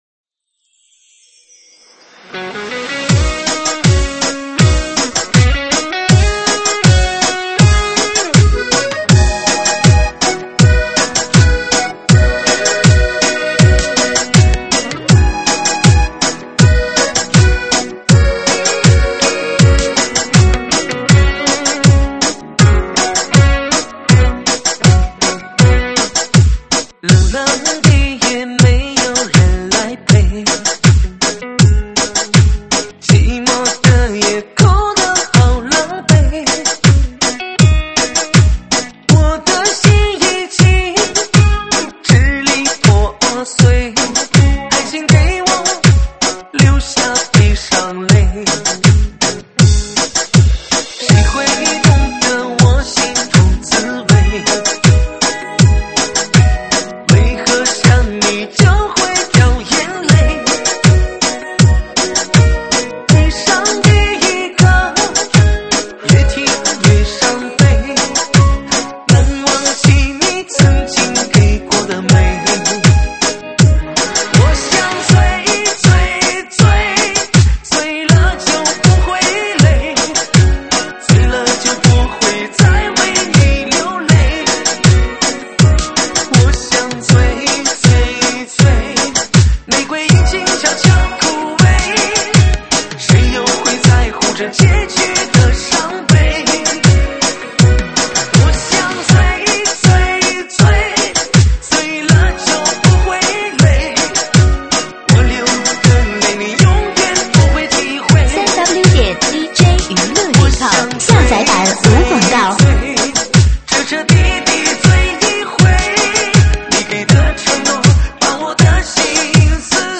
舞曲类别：水兵舞